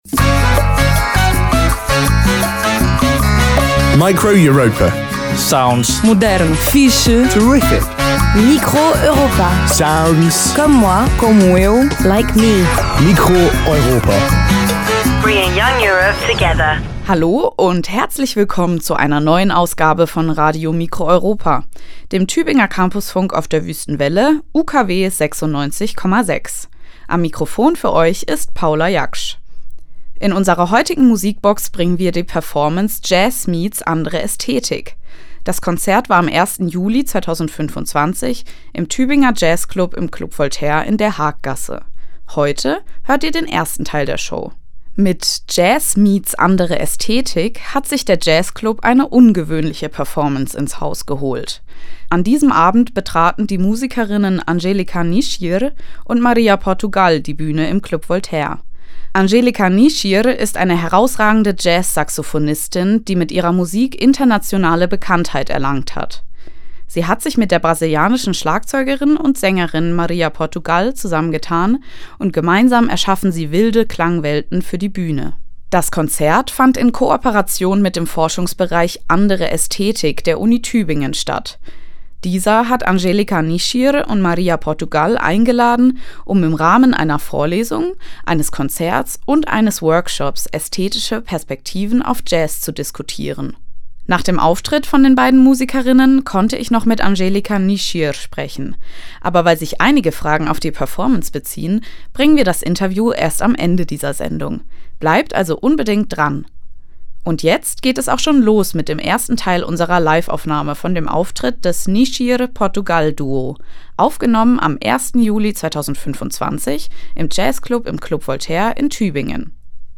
Jazz-Saxophonistin
Schlagzeugerin und Sängerin
Form: Live-Aufzeichnung, geschnitten